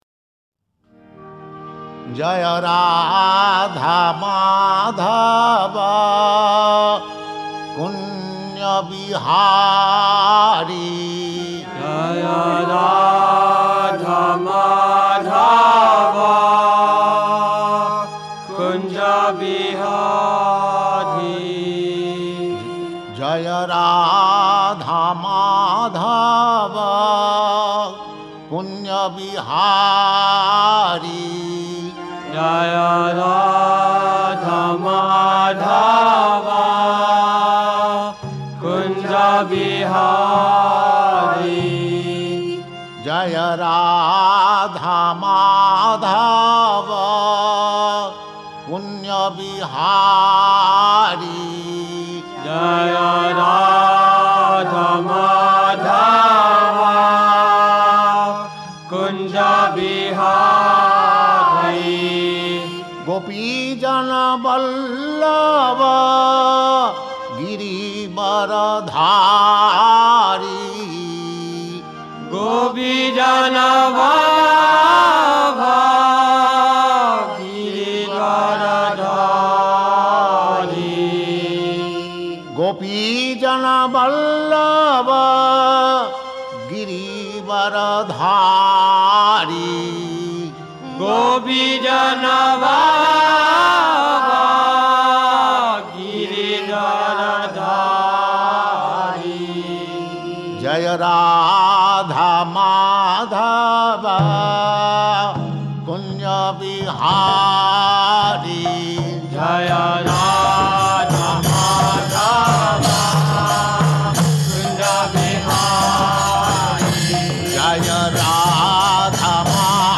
Special features: Performed before Srimad-Bhagavatam and Bhagavad-gita classes.
Performance version v2 — Performed by: Srila Prabhupada
srila-prabhupada-jaya-radha-madhava-symphonic-melody.mp3